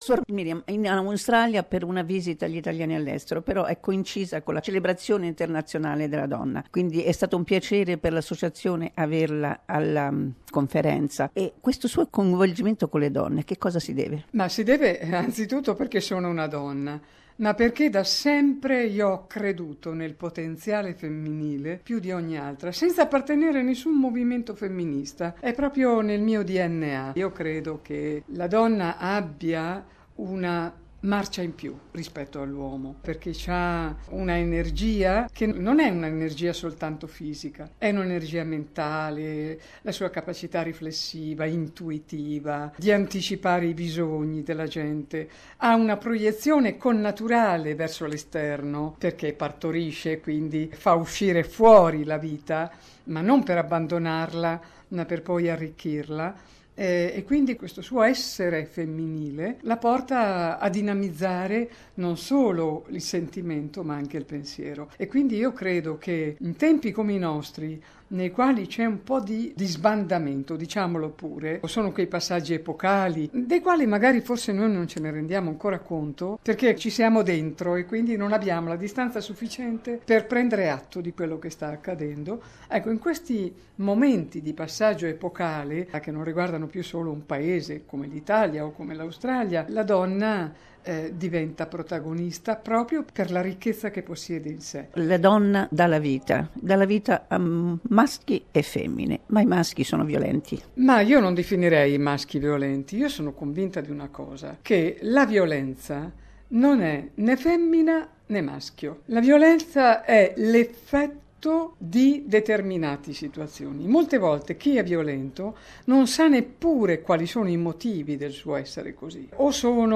Servizio sulla conferenza organizzata dallAssociazione Nazionale Donne Italo-Australiane sul femminicidio, in occasione della Giornata Internazionale della Donna.